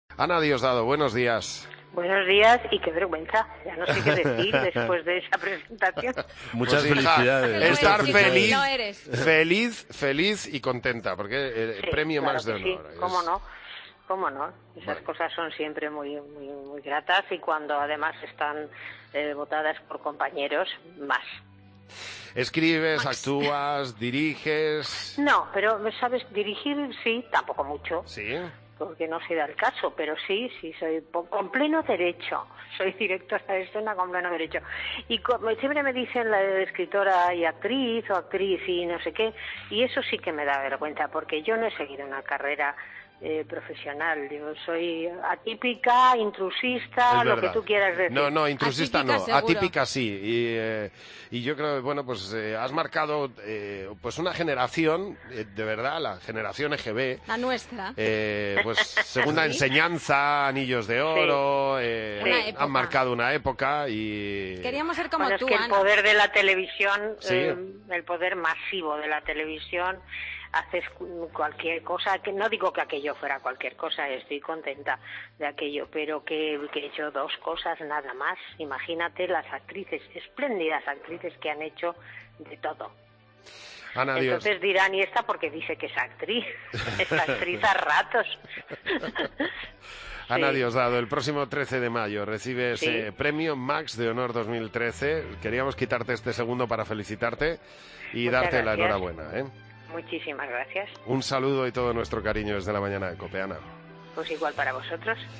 Entrevista de Javi Nieves a Ana Diosdado en 2013: "Soy atípica"